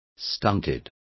Complete with pronunciation of the translation of stunted.